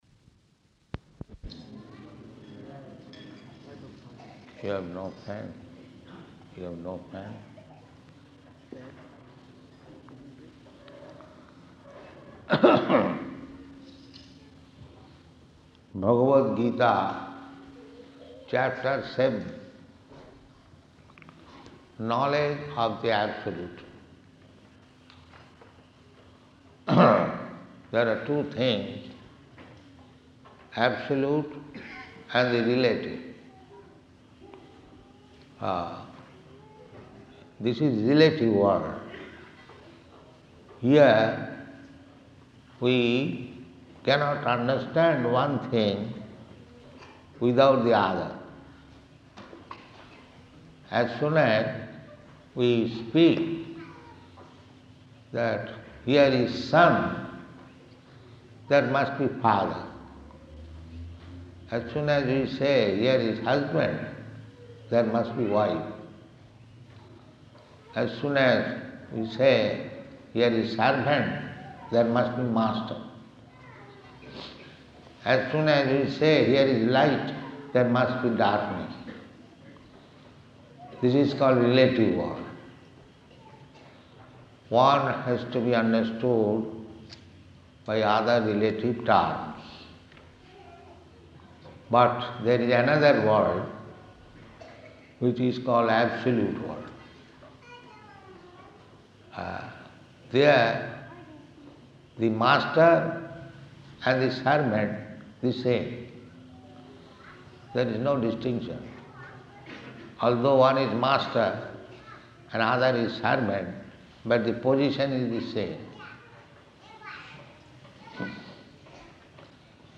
Bhagavad-gītā 7.1 --:-- --:-- Type: Bhagavad-gita Dated: October 9th 1975 Location: Durban Audio file: 751009BG.DUR.mp3 Prabhupāda: [aside] Fan.